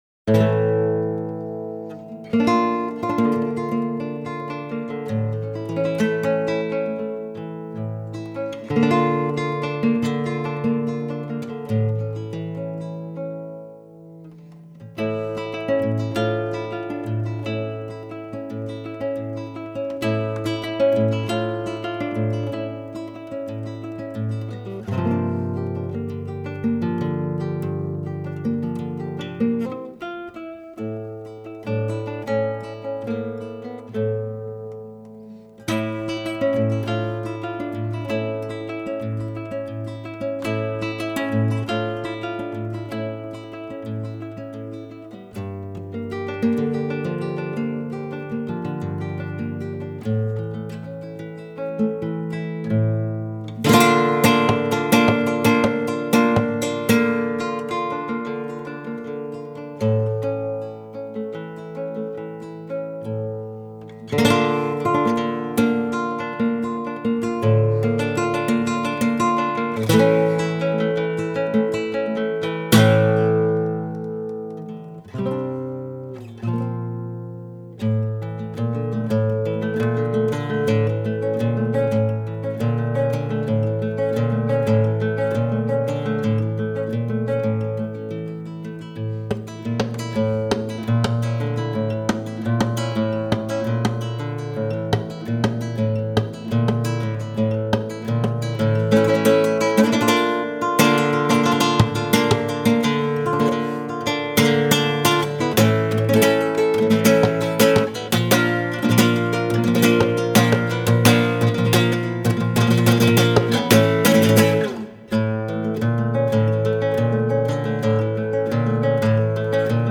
Нью-эйдж